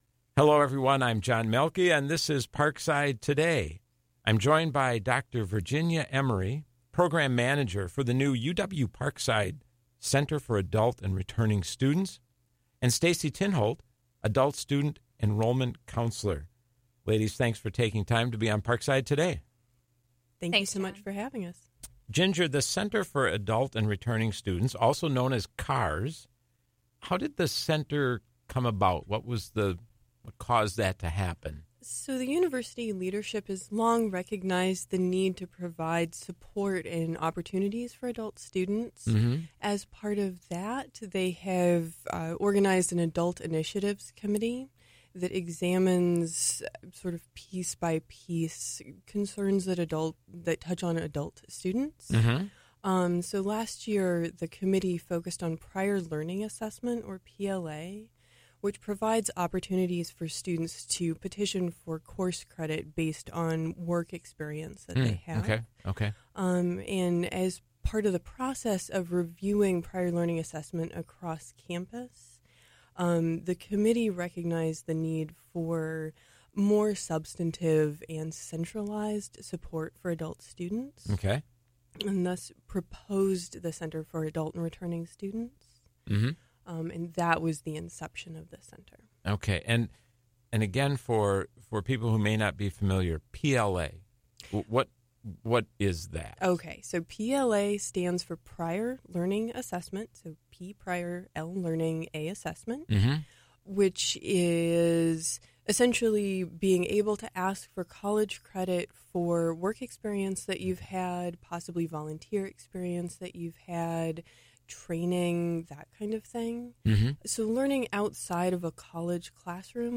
This show originally aired on Tuesday, December 10, at 4 p.m. on WIPZ 101.5 FM.